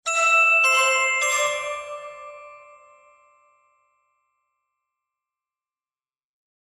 ECD_Klangfolge_Bahnsteig.mp3